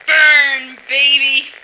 flak_m/sounds/female1/int/F1burnbaby.ogg at df55aa4cc7d3ba01508fffcb9cda66b0a6399f86